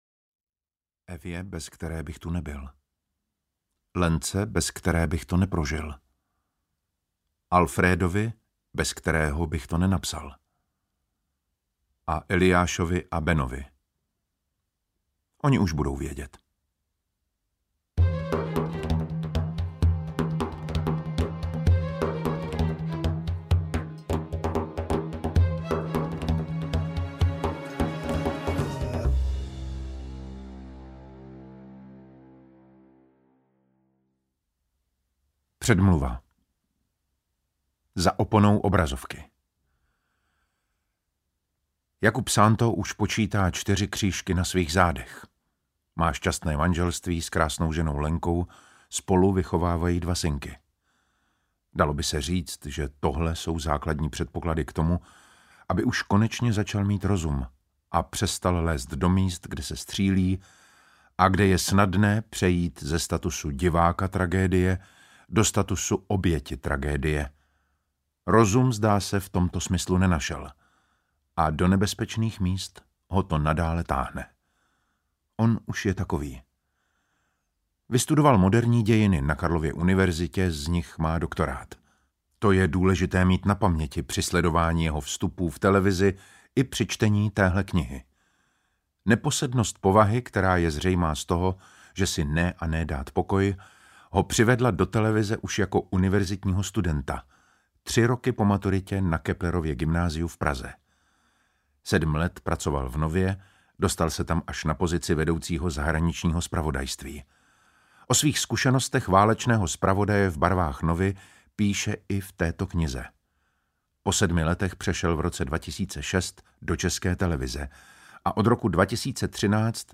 Za oponou války audiokniha
Ukázka z knihy
• InterpretDavid Matásek